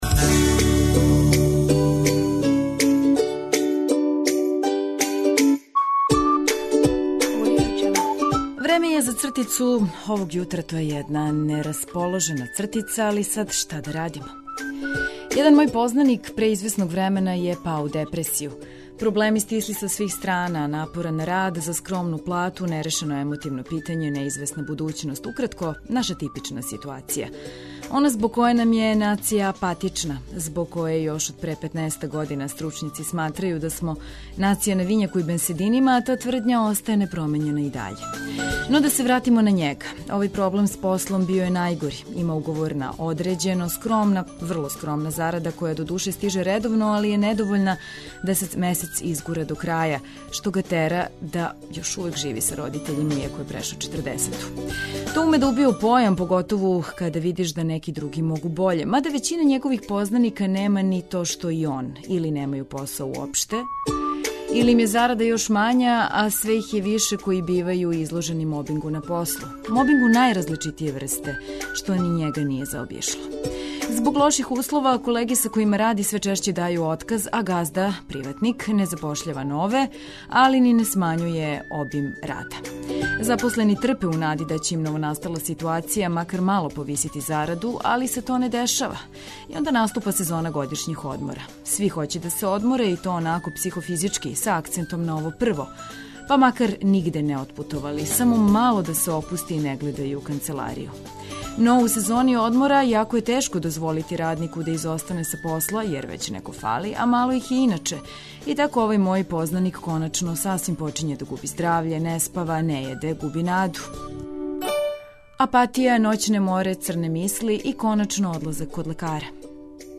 Тако и овог јутра сервирамо музику за размрдавање и пуно разлога за осмех.